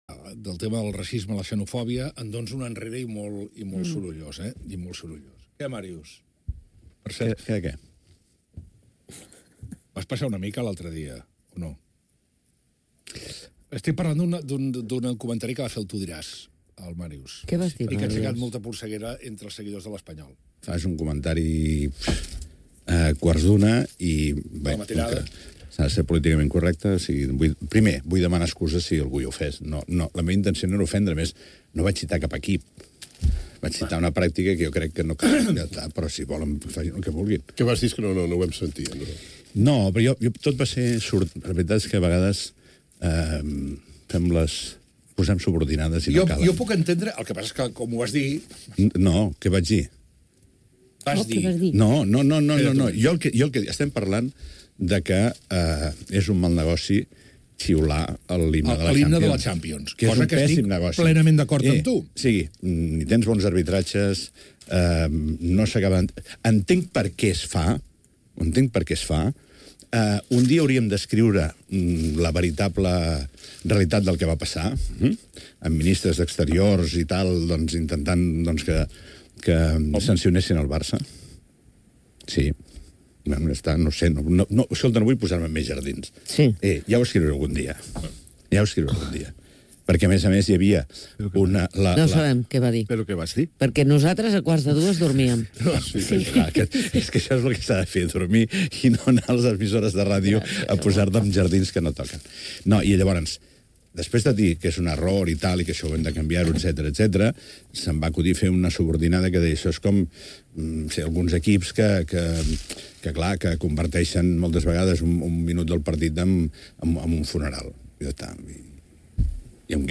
durante su participación en una nueva tertulia, la d’El món a RAC1